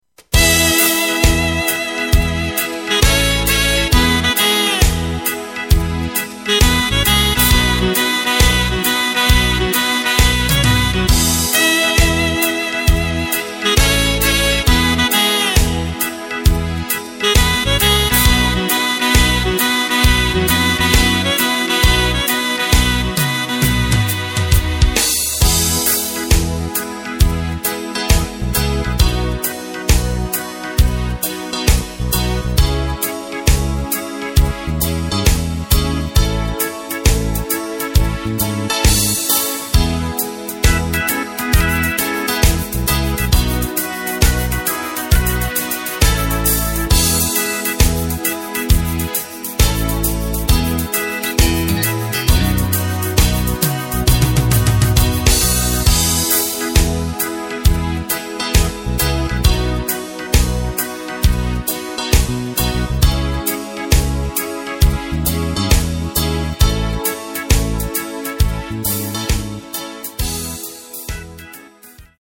Takt: 4/4 Tempo: 67.00 Tonart: E
3:48 min Austropop aus dem Jahr 1985!